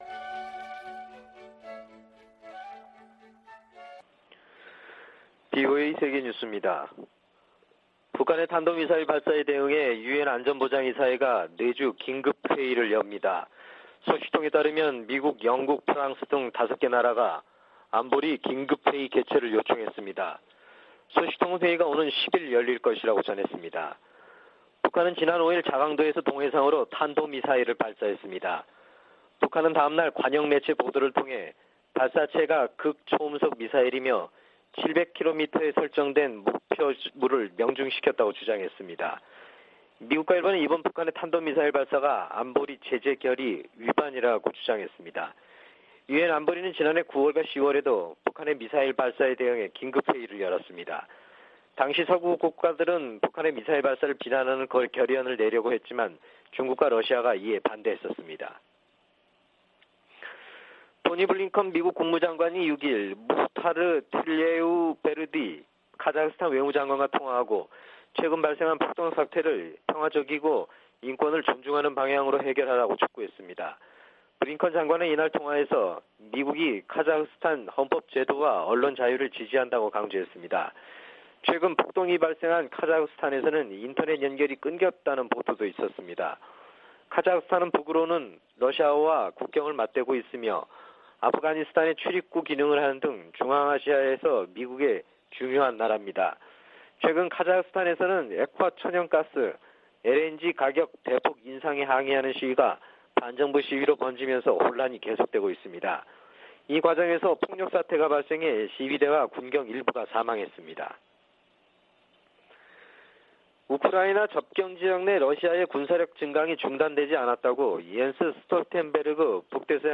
VOA 한국어 아침 뉴스 프로그램 '워싱턴 뉴스 광장' 2021년 1월 8일 방송입니다. 미국과 일본은 북한의 핵과 미사일 개발에 강한 우려를 표하고 유엔 안보리 결의 준수를 촉구했습니다. 미 국방부는 북한의 미사일 시험발사에 관해 구체적 성격을 평가하고 있다며, 어떤 새로운 능력도 심각하게 받아들인다고 밝혔습니다. 미 국무부는 북한 탄도미사일 관련 안보리 소집 여부에 대해, 동맹과 함께 후속 조치를 논의 중이라고 밝혔습니다.